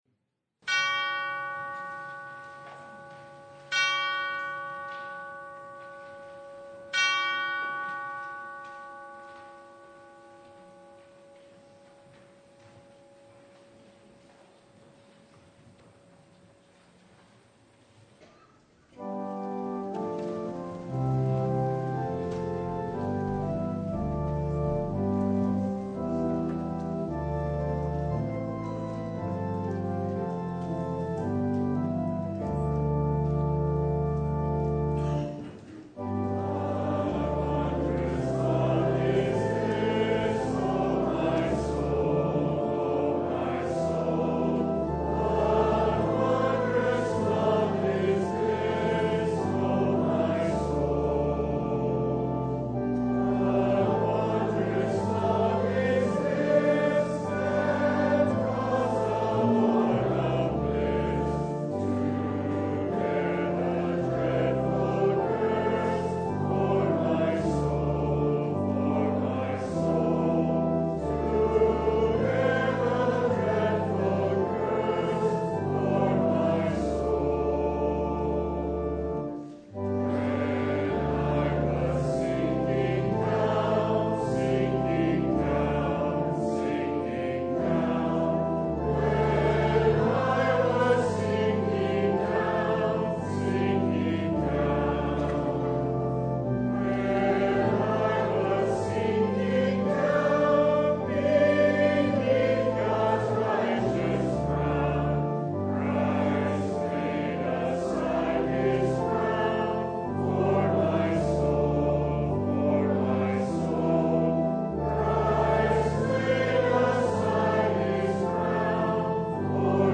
Preacher: Visiting Pastor Passage: Luke 17:11–19 Service Type
Download Files Bulletin Topics: Full Service « Sin, Forgiveness, and Faith Presents or Presence?